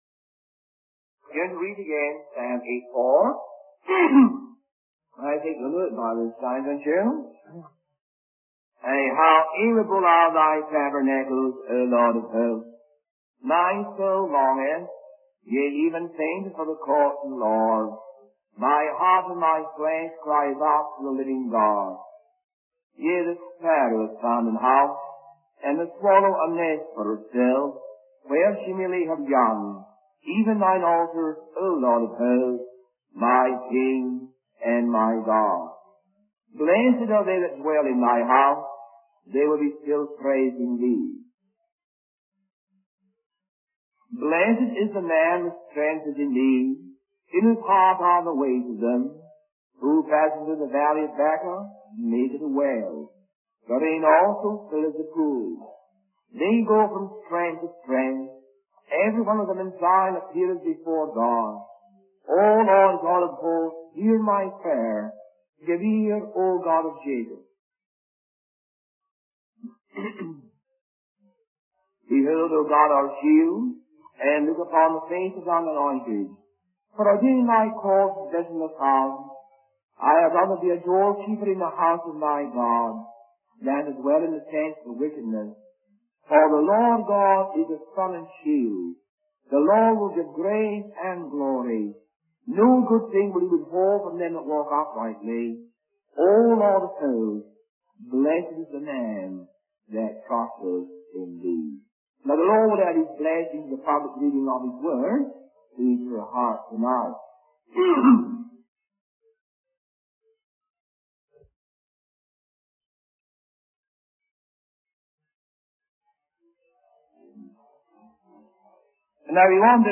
In this sermon, the preacher emphasizes the importance of living a useful and productive life according to God's great purpose.